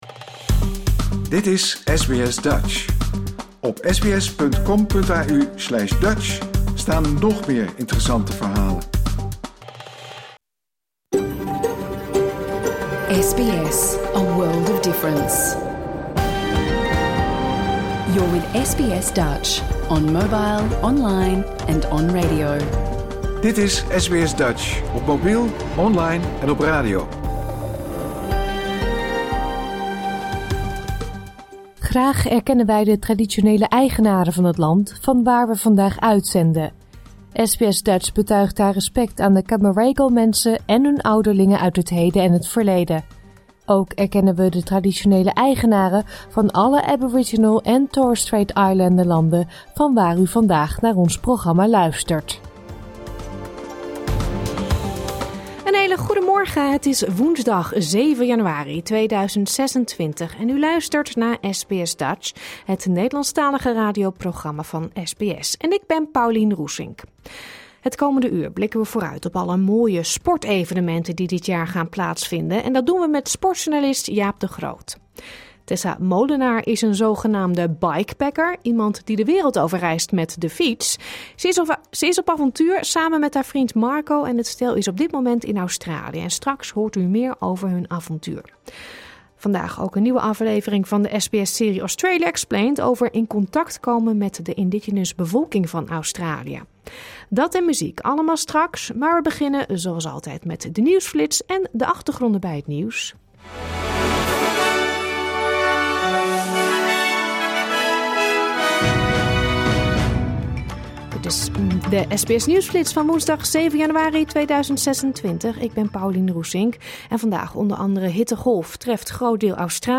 Luister hier de uitzending van woensdag 7 januari 2026 (bijna) integraal terug.